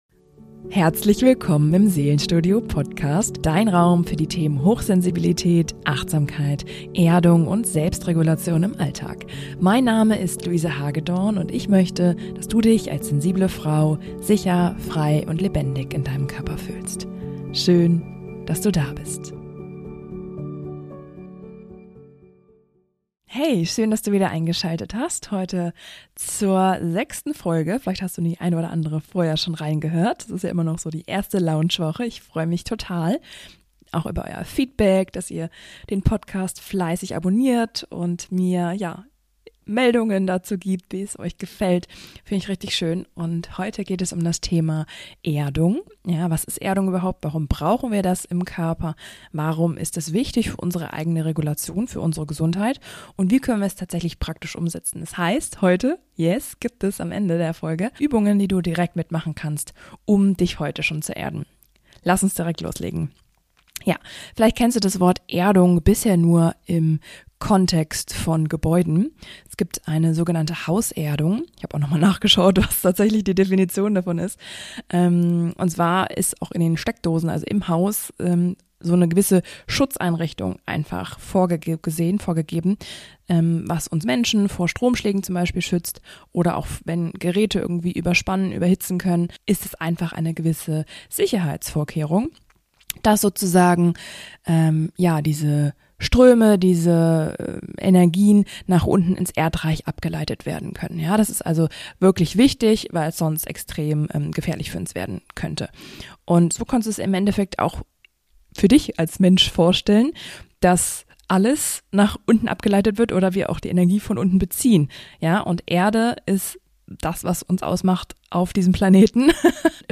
Danach führe ich dich 15 Minuten lang praktisch durch eine Erdungs-Session mit sanften Dehnungen, Yoga, Hüpfen, Schwingen, Atemübungen und Entspannung.